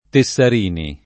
Tessarini [ te SS ar & ni ] cogn.